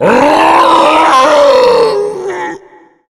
burer_telekinetic_1.ogg